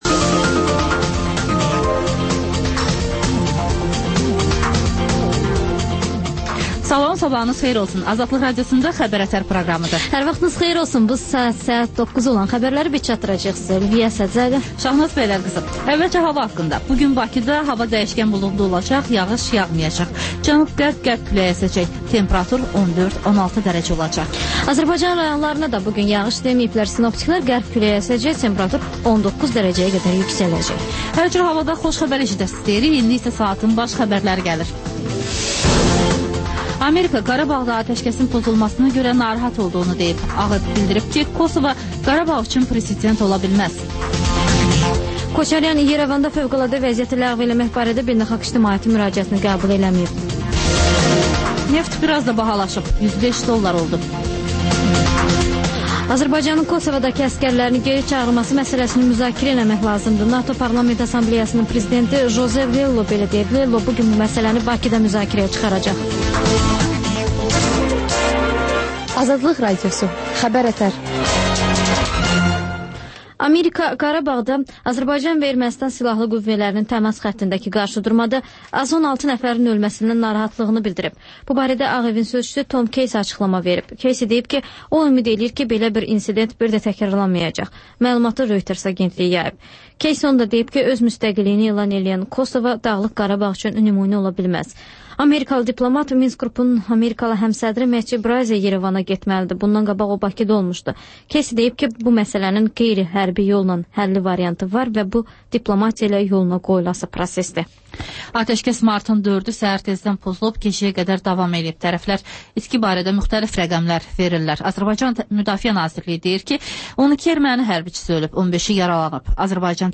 Xəbər-ətər: xəbərlər, müsahibələr, daha sonra ŞƏFFAFLIQ: Korrupsiya haqqında xüsusi veriliş.